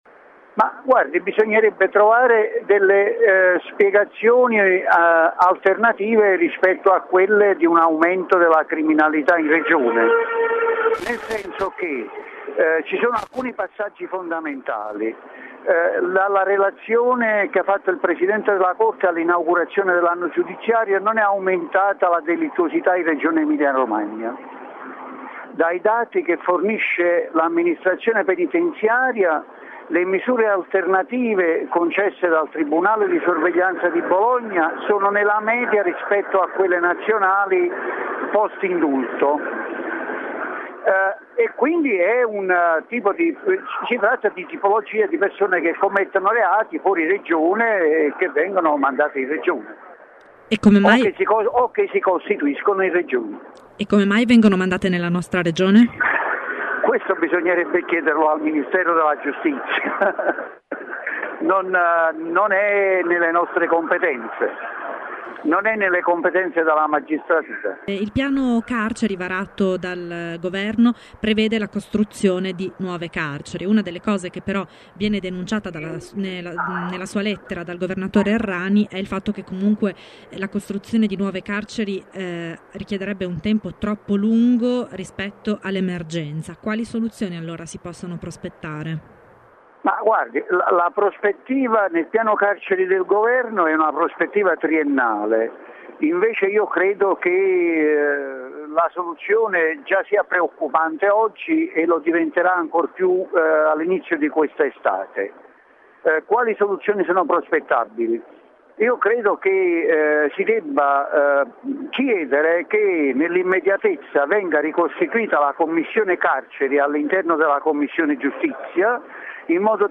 Ascolta Francesco Maisto